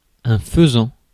Ääntäminen
Synonyymit coq faisan brigandeau Ääntäminen France: IPA: /fə.zɑ̃/ Tuntematon aksentti: IPA: /fǝzɑ̃/ Haettu sana löytyi näillä lähdekielillä: ranska Käännös Konteksti Substantiivit 1. фазан {m} (fazan) eläintiede Suku: m .